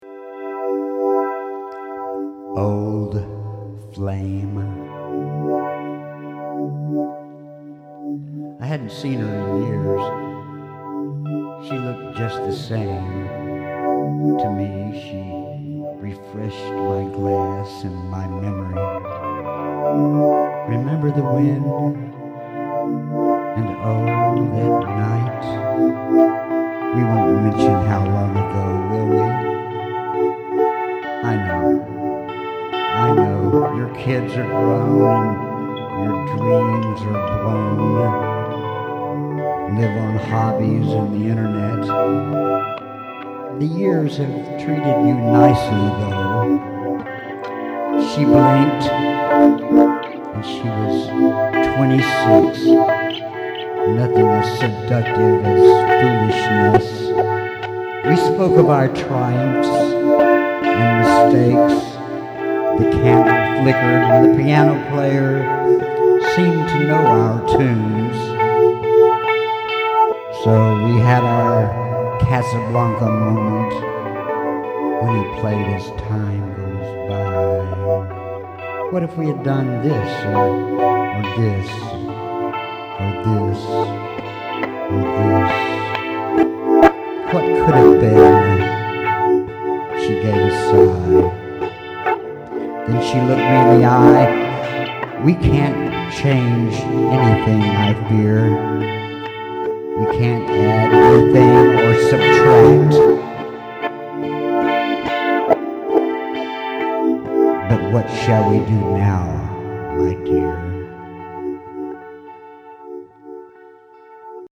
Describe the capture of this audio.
I can hardly hear your voice. I'm not happy with the levels either, but it was done live